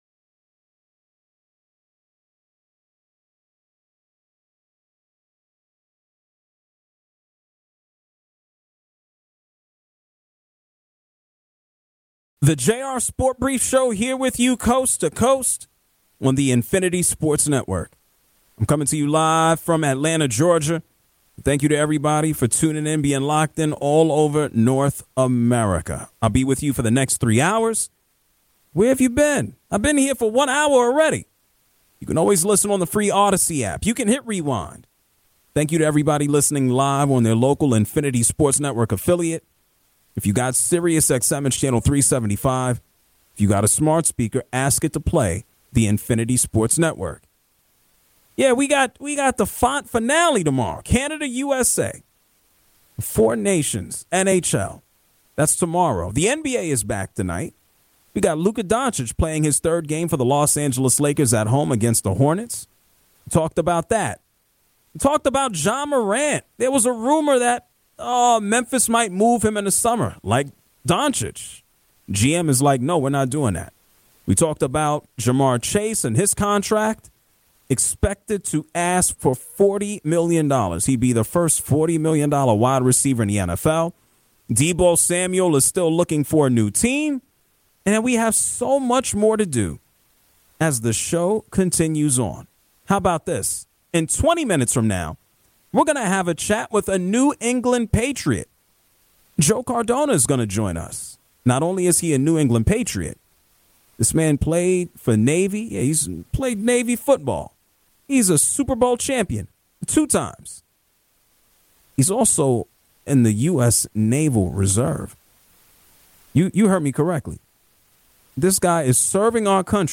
Interview with Patriots long-snapper Joe Cardona and discourse on Victor Wembanyama's candidacy for face of the NBA, too!